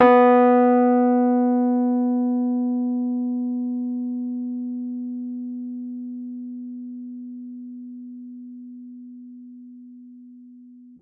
piano-sounds-dev
Rhodes_MK1